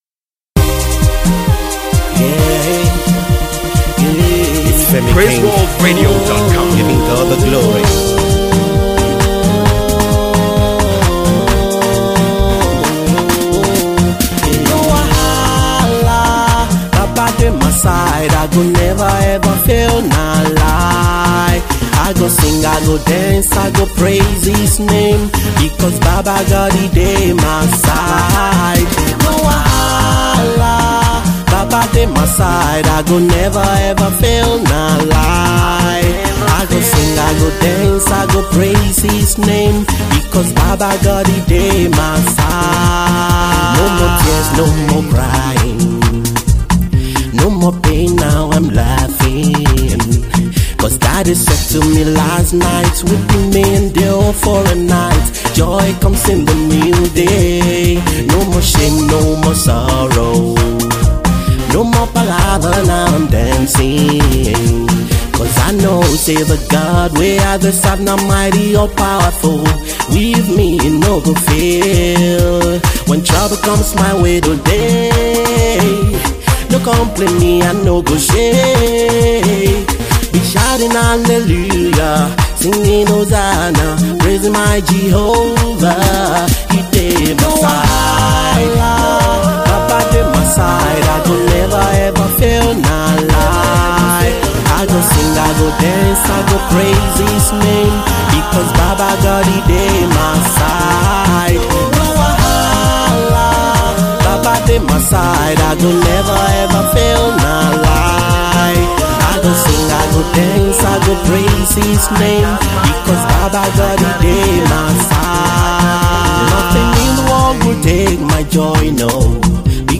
gospel recording artist